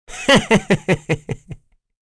Mitra-Vox_Happy1.wav